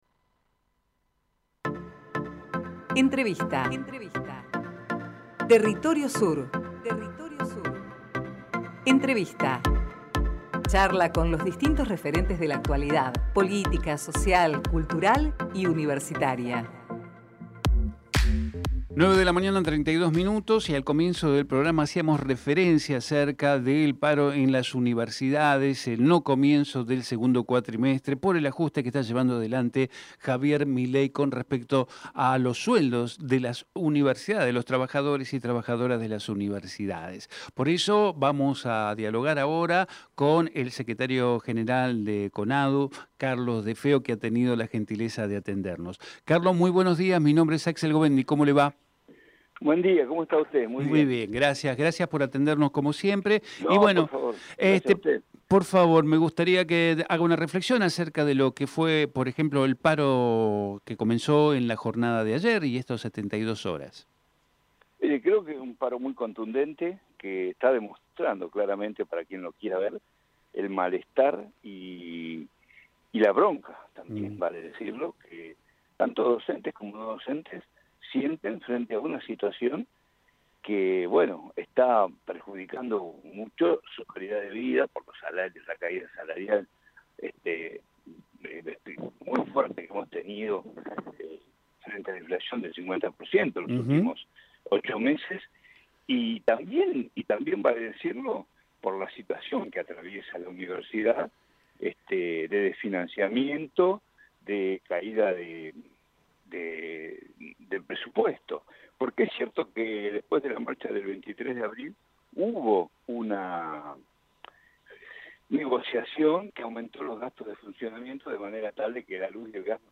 Compartimos con ustedes la entrevista realizada en Territorio Sur